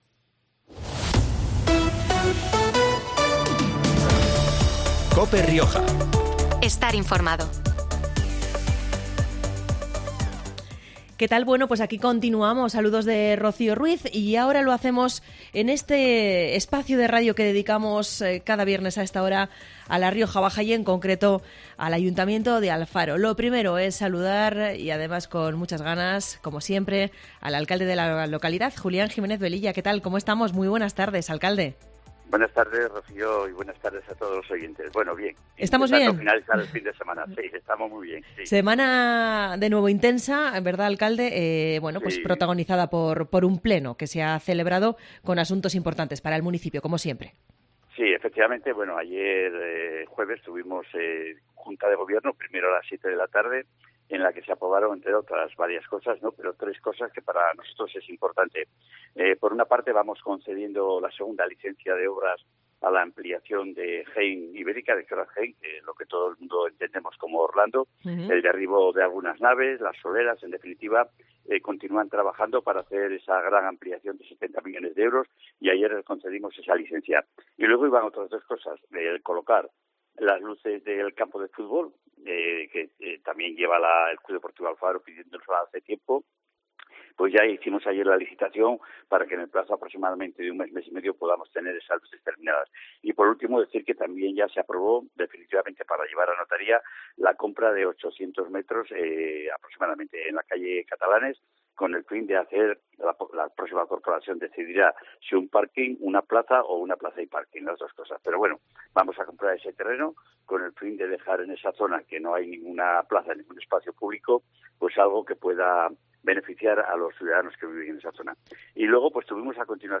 Julián Jiménez Velilla, alcalde de Alfaro, analiza en COPE la actualidad del municipio